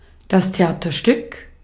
Ääntäminen
Synonyymit spelbricka spelpjäs stycke lätt pjäs Ääntäminen : IPA: [ɛn pjeːs] Tuntematon aksentti: IPA: /pjeːs/ Haettu sana löytyi näillä lähdekielillä: ruotsi Käännös Ääninäyte Substantiivit 1.